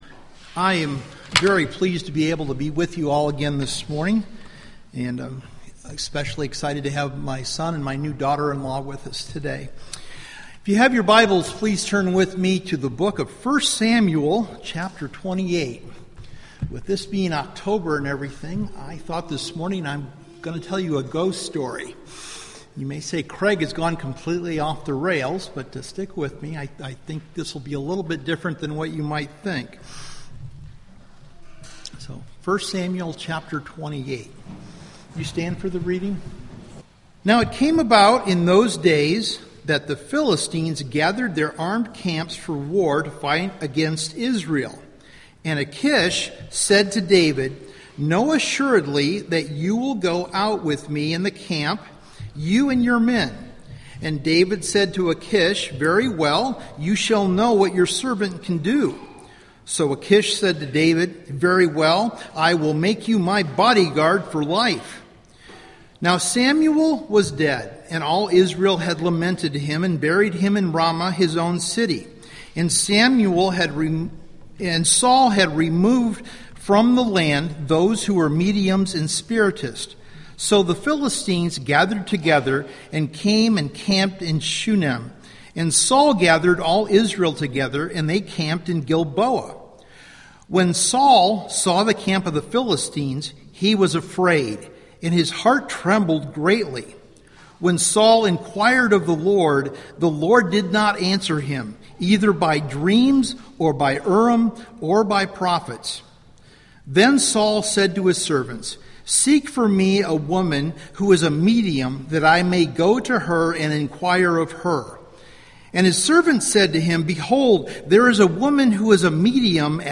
Preached Oct. 7